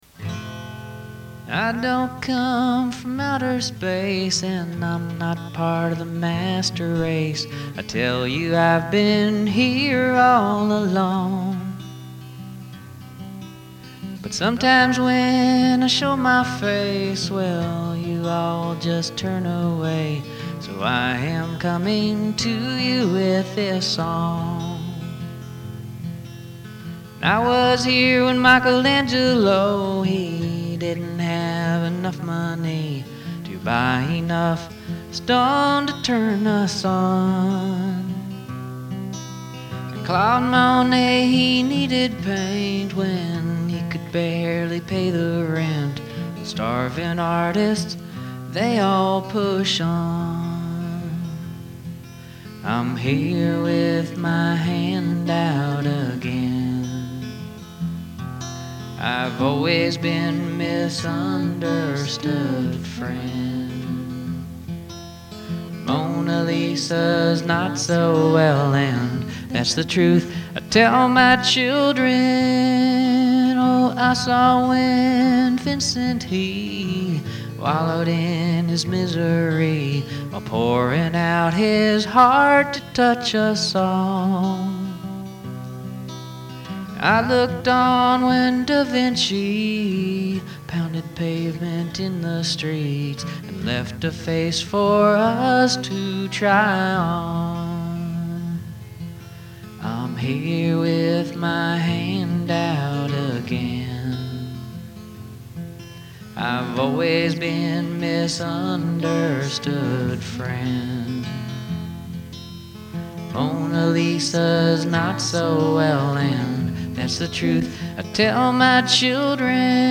is a song